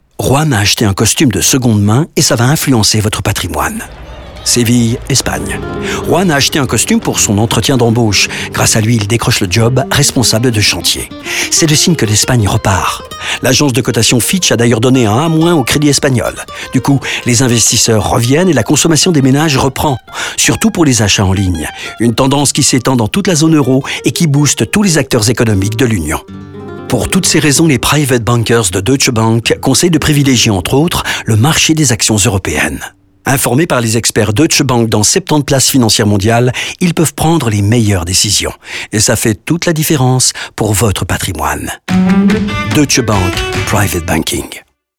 Nous prenons également le temps de développer les histoires de Rinku, Søren, Juan et Edward dans des spots radio de 45 secondes.